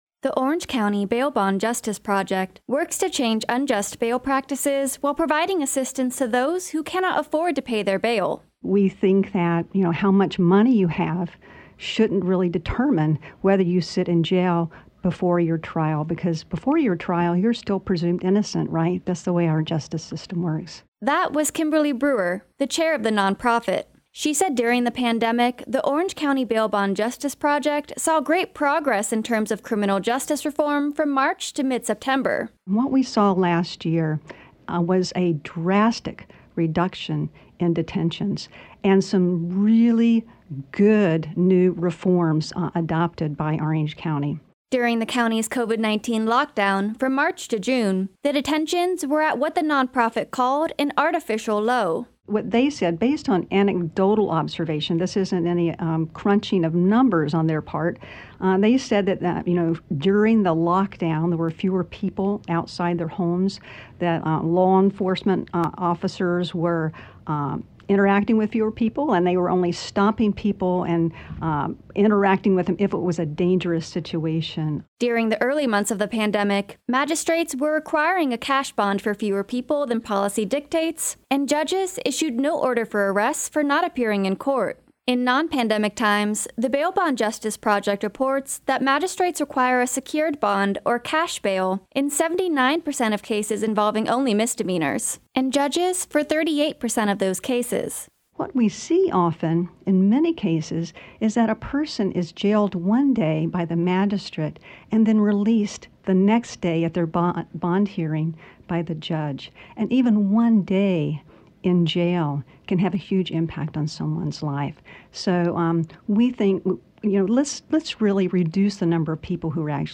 full conversation with 97.9 The Hill here.